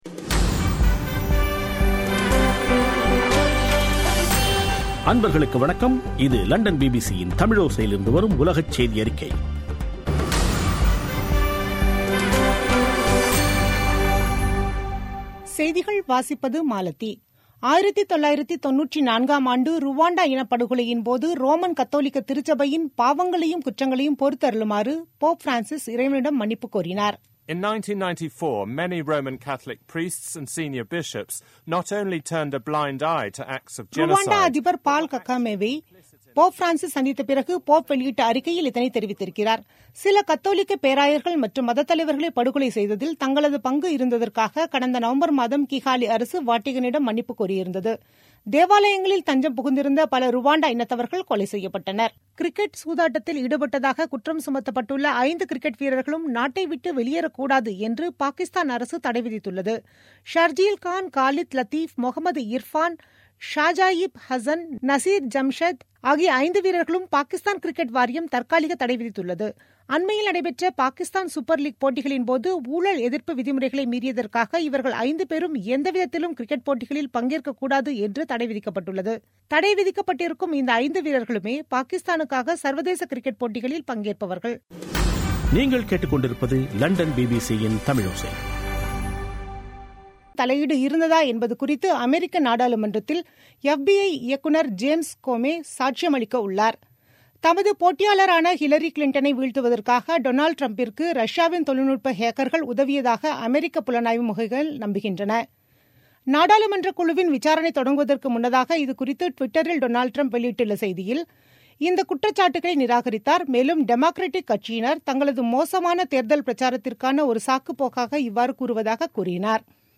பிபிசி தமிழோசை செய்தியறிக்கை (20/03/2017)